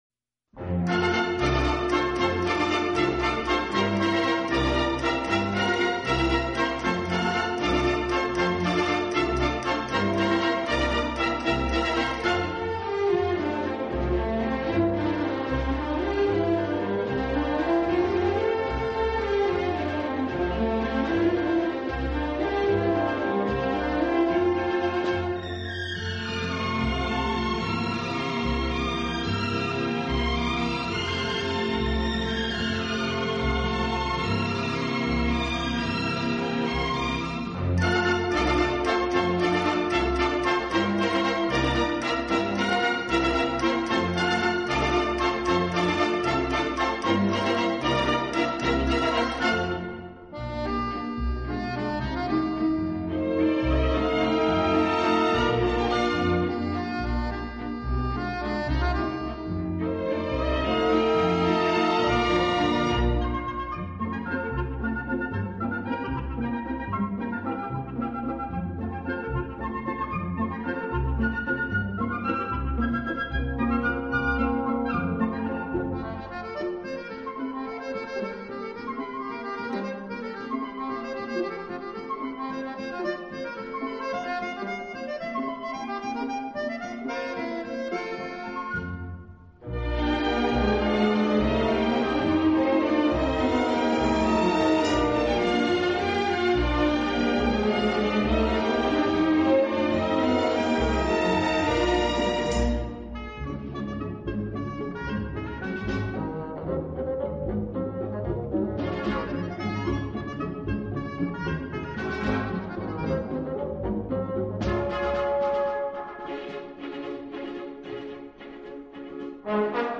【顶级轻音乐】
一波一浪的小提琴，静若花落水面，动如飞瀑流泻。
这个乐团的演奏风格流畅舒展，
旋律优美、动听，音响华丽丰满。